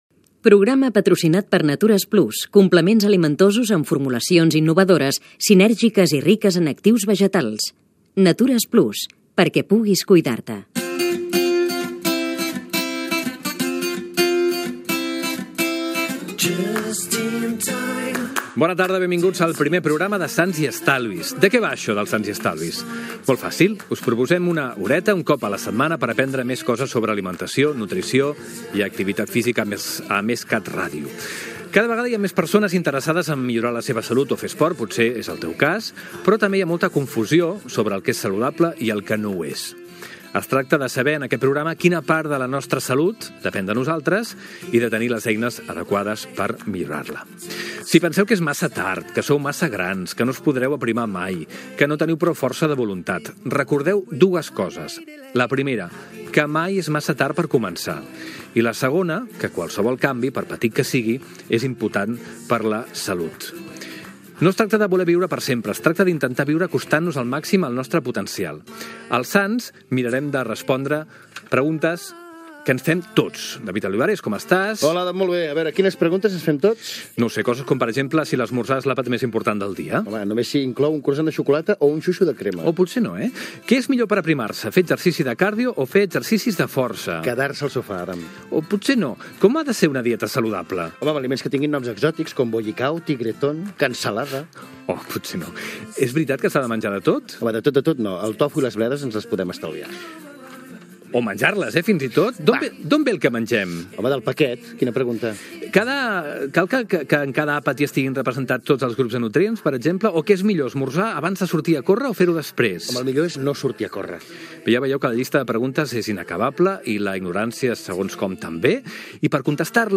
Publicitat, presentació del primer programa amb els seus objectius. Sumari de continguts.
Divulgació